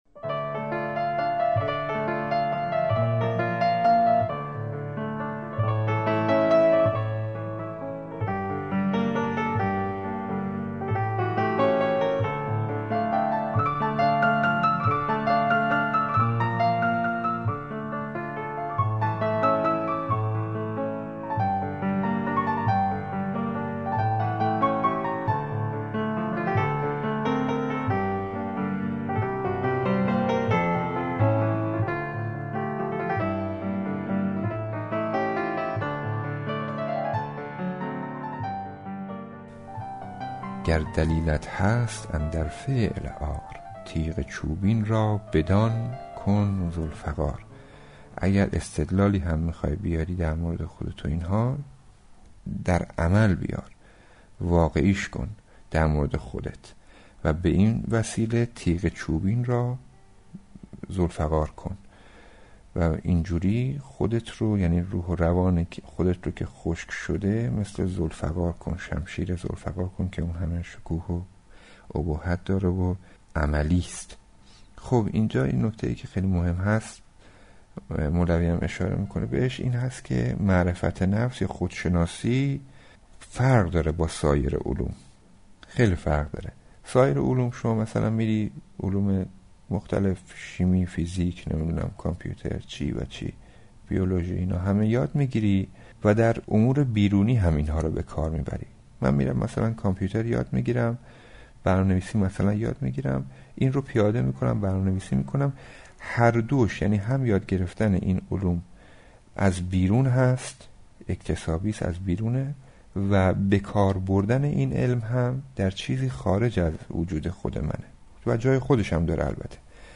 + برگرفته از فایل a از جلسه ۱۱۹ شرح مثنوی معنوی مولانا علمهای اهل دل حمالشان علمهای اهل تن احمالشان علم چون بر دل زند یاری شود علم چون بر تن زند باری شود نظـرات علم‌های اهل دل